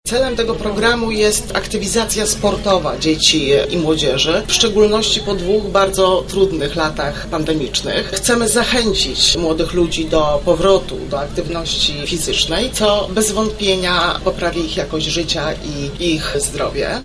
We wtorek w Lublinie odbył się turniej dla dzieci połączony z konferencją Powszechnego Zakładu Ubezpieczeń dotycząca programu ,,Dobra drużyna PZU”.